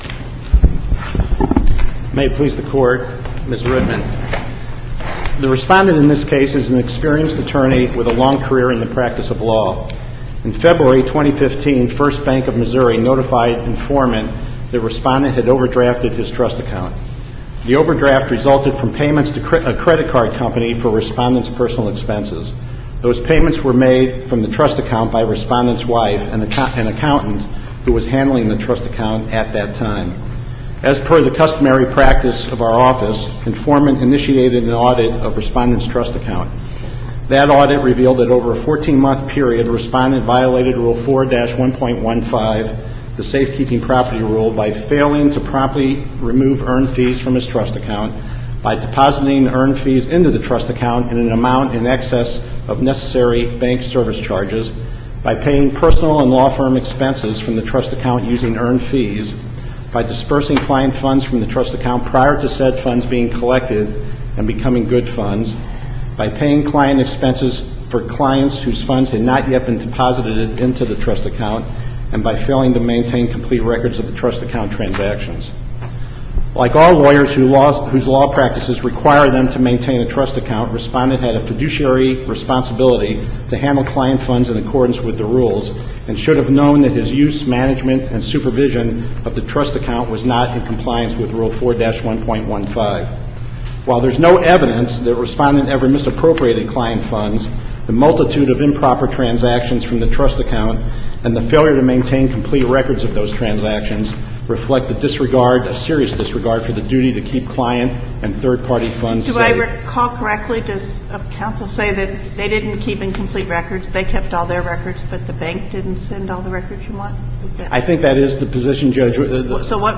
MP3 audio file of arguments in SC96474